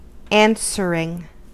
Ääntäminen
Vaihtoehtoiset kirjoitusmuodot (rikkinäinen englanti) answerin' Ääntäminen US Tuntematon aksentti: IPA : /ˈɑːn.sə.ɹɪŋ/ IPA : /ˈæn.sɚ.ɹɪŋ/ Haettu sana löytyi näillä lähdekielillä: englanti Käännöksiä ei löytynyt valitulle kohdekielelle.